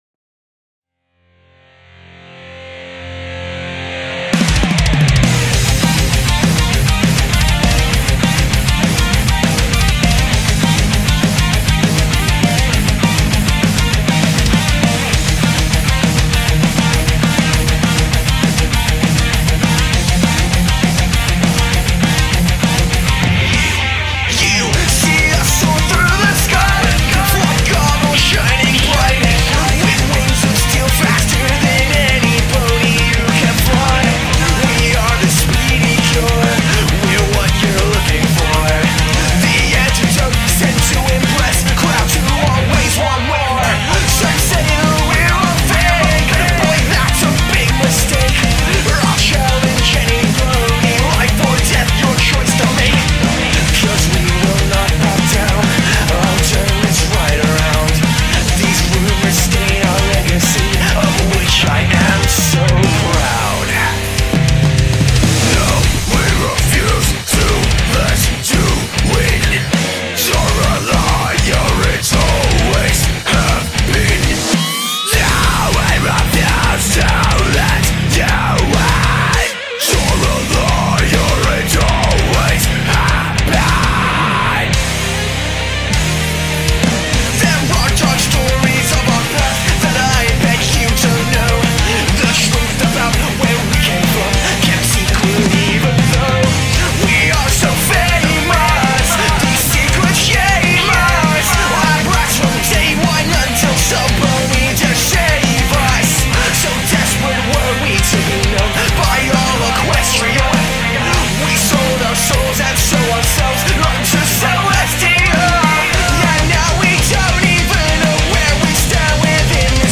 scream vocals